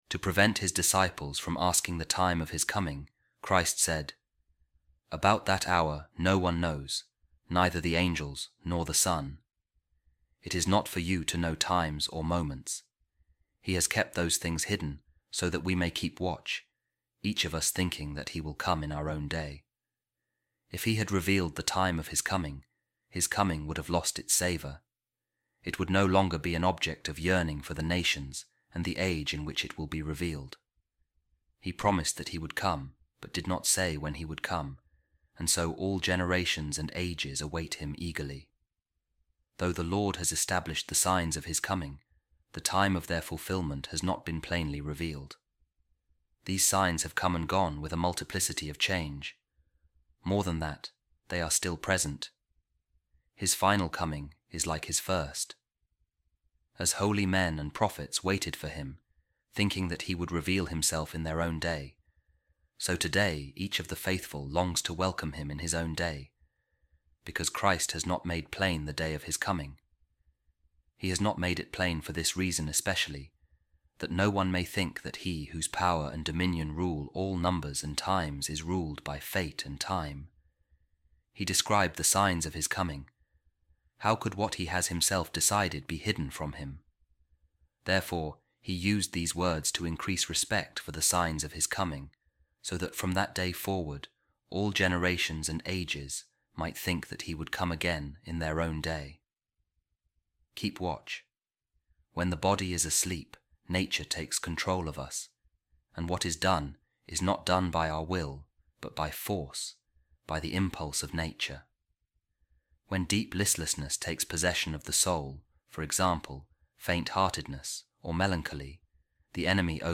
A Reading From Saint Ephraem’s Commentary On The Diatessaron | Keep Watch: He Is To Come Again